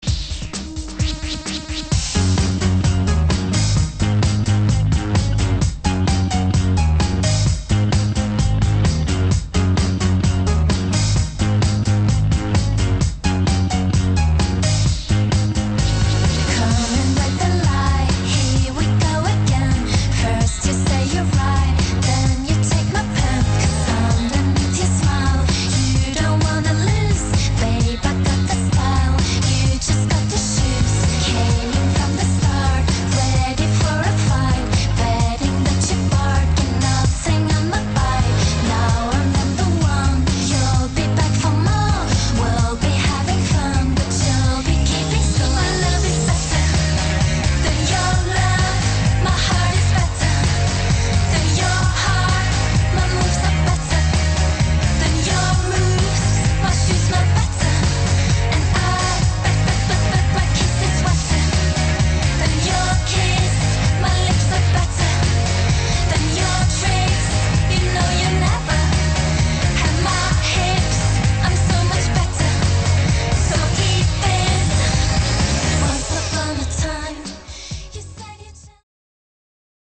[ ELECTRO POP / DUBSTEP ]